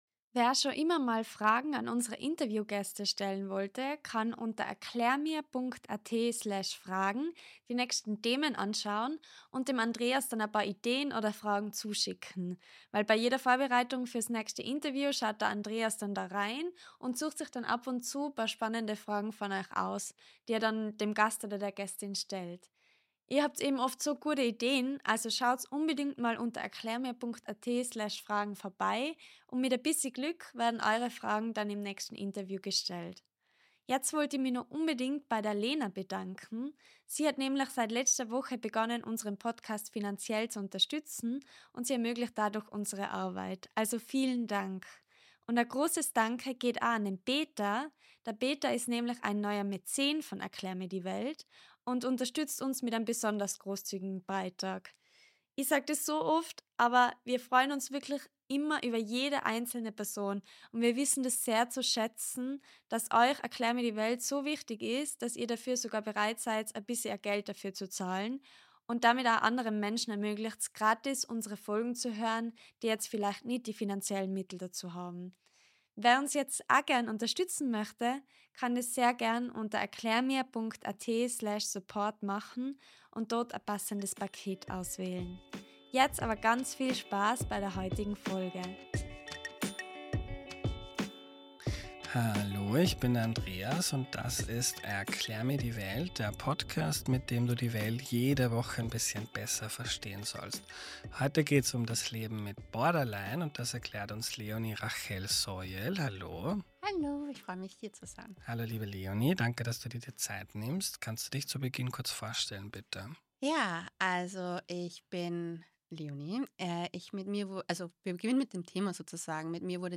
Der Schlüssel: Den »A-B-Moment« erkennen und zwischen destruktivem Impuls und gesunder Wahl entscheiden. Ein Gespräch, das Hoffnung macht und Stigma abbaut.